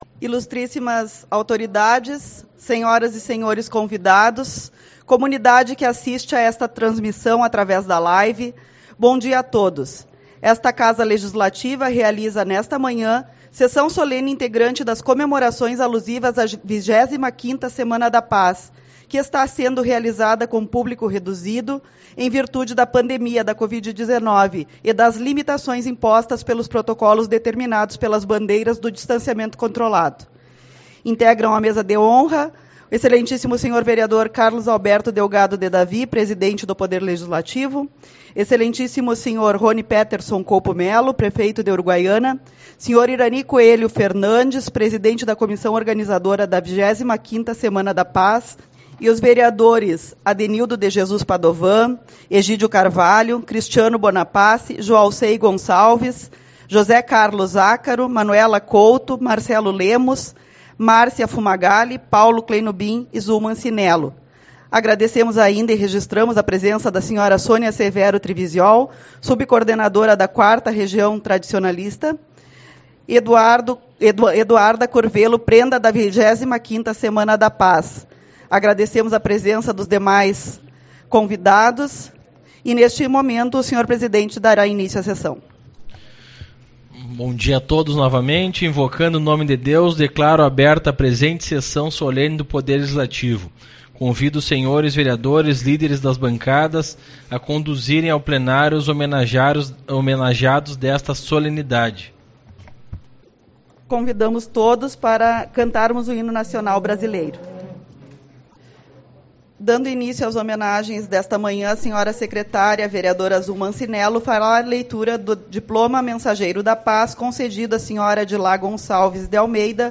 25/02 - Sessão Solene-Semana da Paz